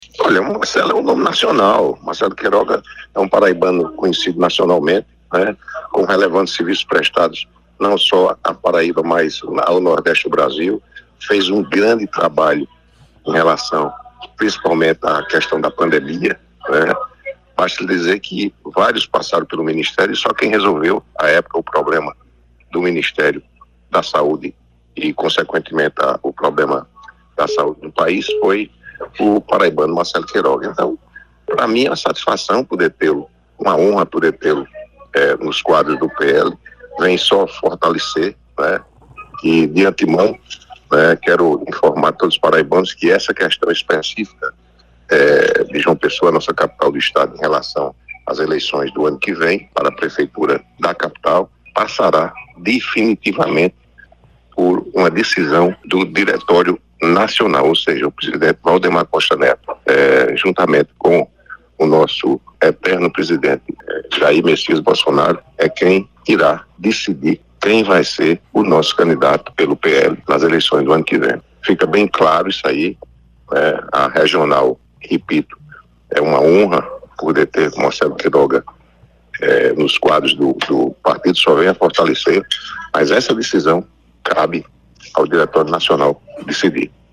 Os comentários do parlamentar foram registrados pelo programa Correio Debate, da 98 FM, de João Pessoa, nesta sexta-feira (12/05).